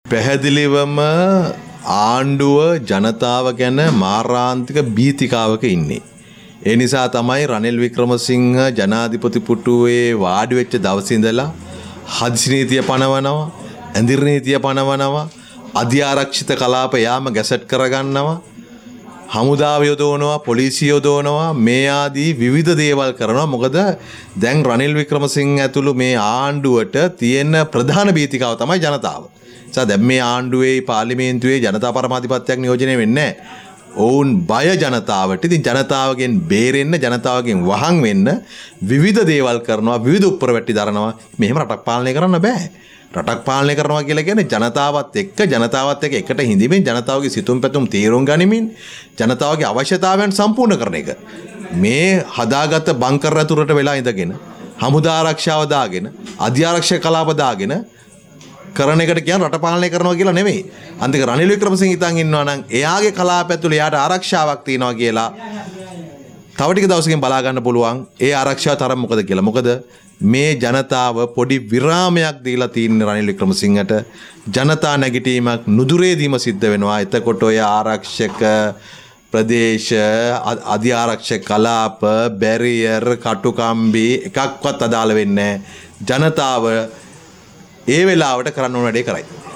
හොරණ ප්‍රදේශයේ පැවති ජන හමුවකට එක වෙමින් නලින්ද ජයතිස්ස මහතා මේ අදහස් පල කළා.